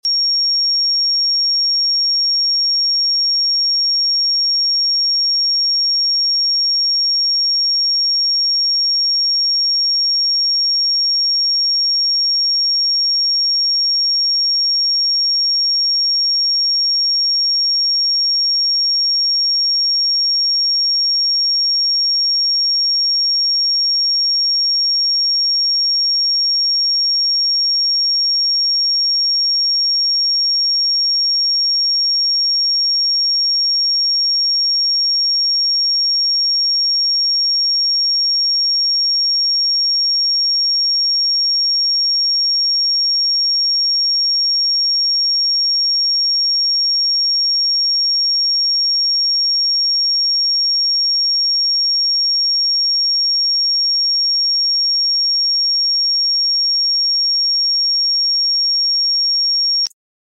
I have prepared a 5555hz sound effects free download
I have prepared a 5555hz frequency for youw,let it guide you to a calmstate,don’t forget to share your journey